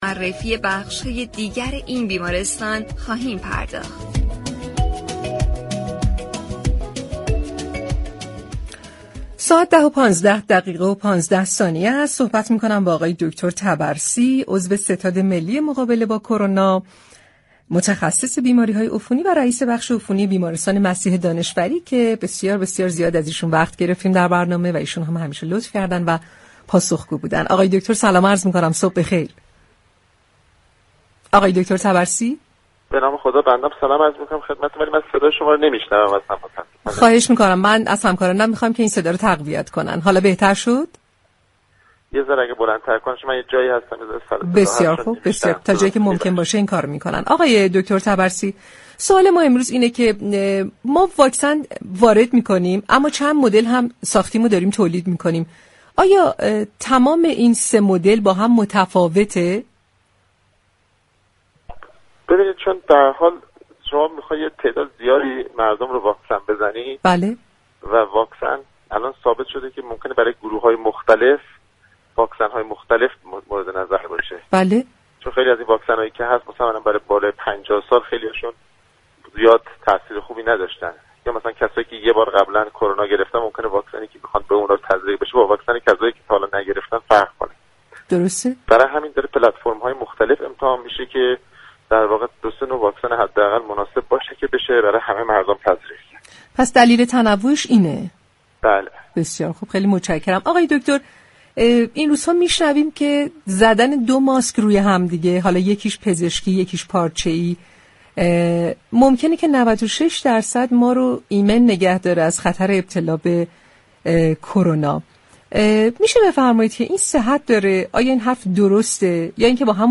در گفتگو با برنامه « تهران ما سلامت»